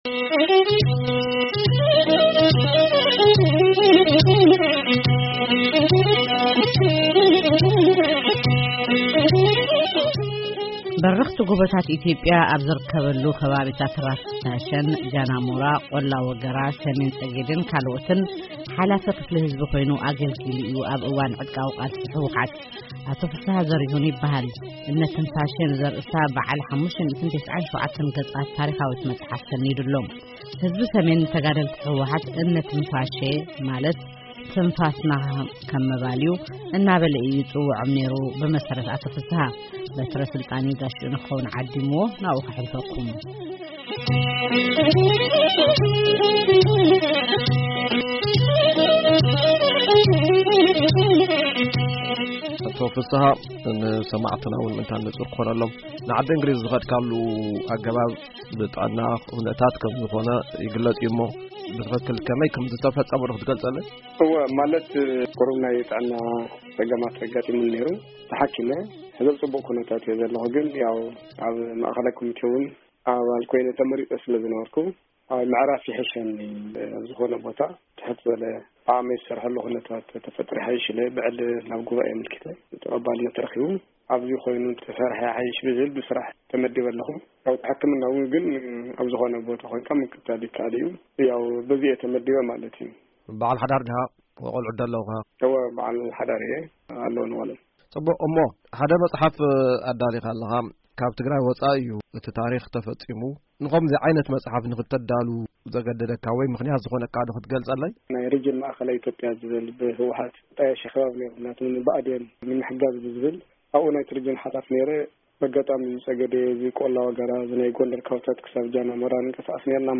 ዕላል